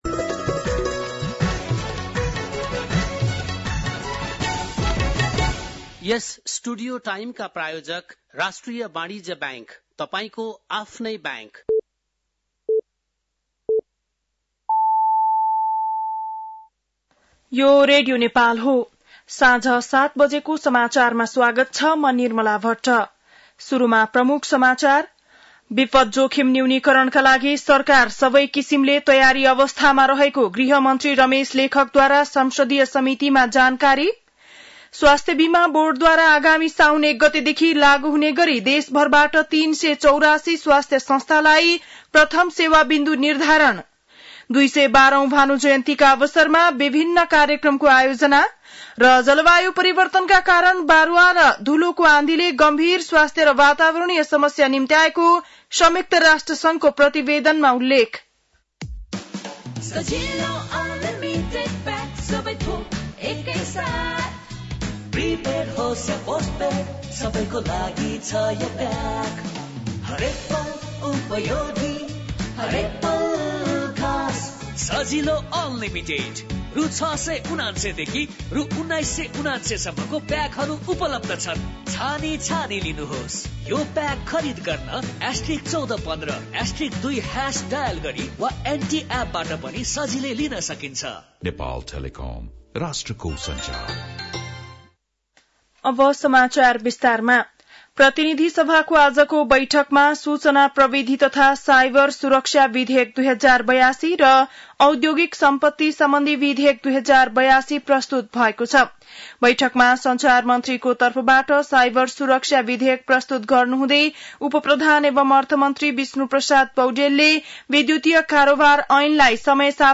बेलुकी ७ बजेको नेपाली समाचार : २९ असार , २०८२
7-pm-nepali-news-3-29.mp3